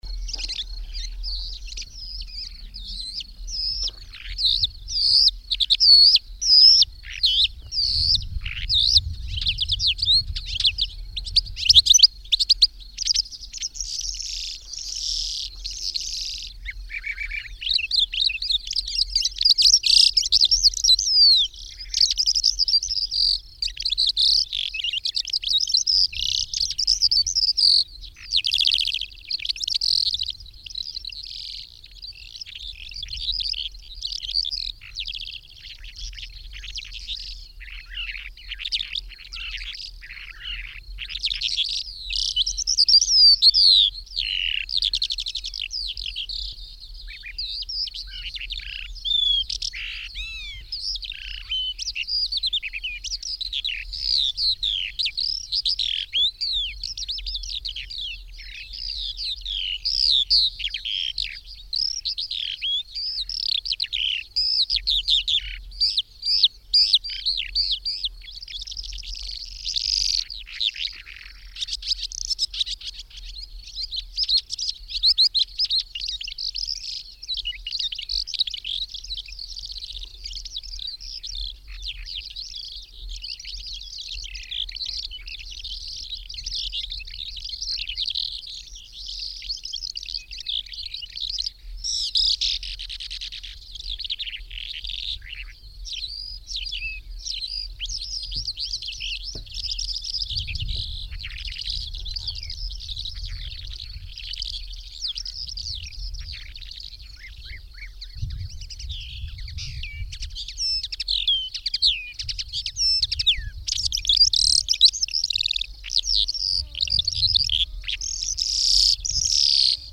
Ciocarlia-5-de-Baragan-Melanocorypha-calandra.mp3